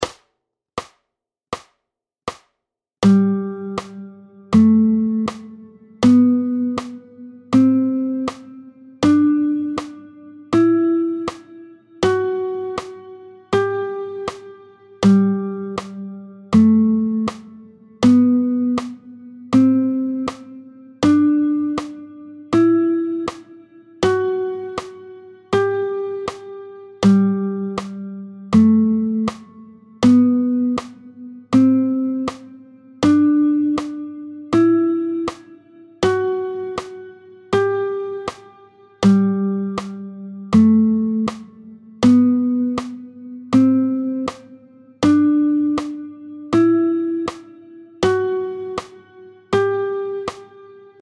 Luth
09-03 La gamme de Sol majeur, tempo 80